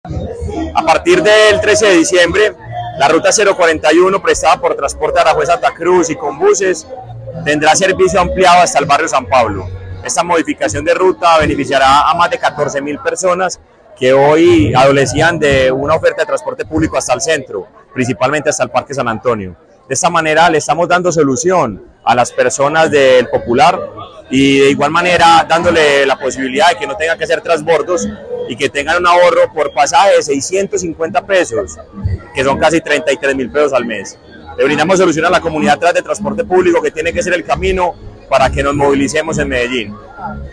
Secretario_movilidad.mp3